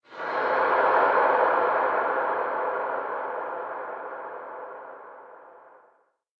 cave4.wav